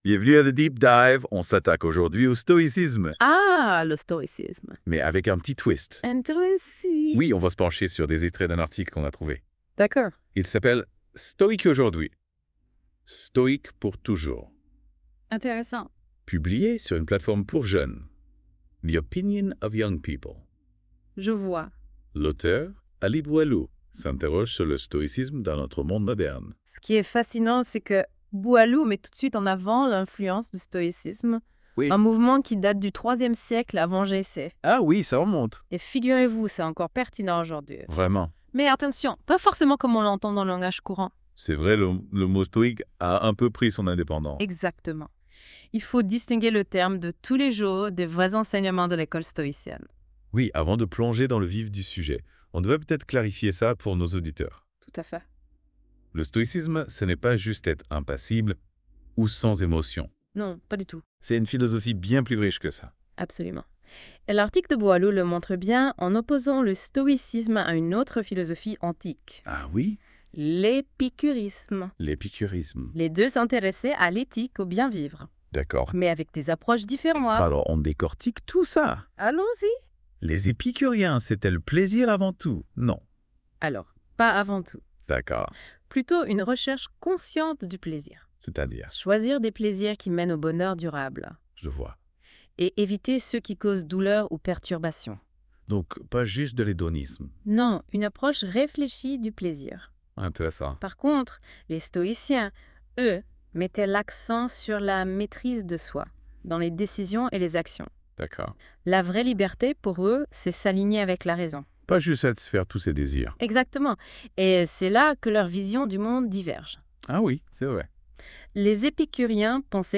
Débat à écouter (32.04 Mo)